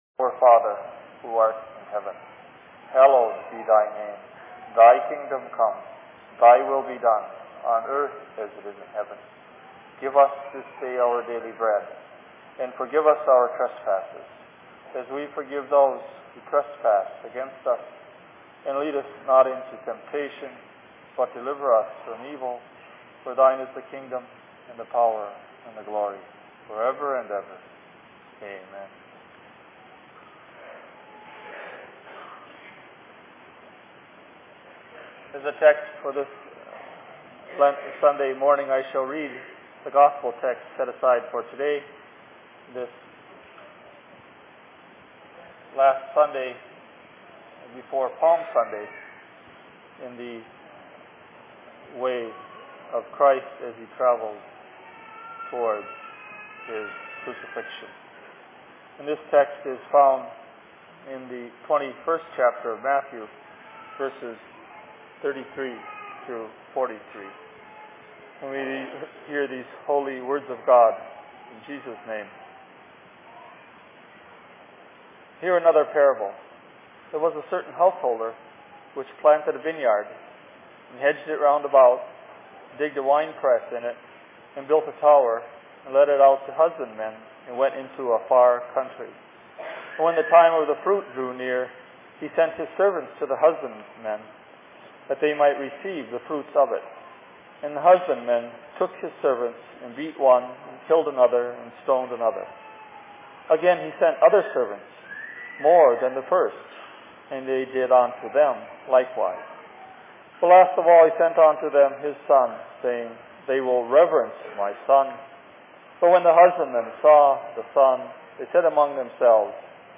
Sermon in Minneapolis 01.04.2001
Location: LLC Minneapolis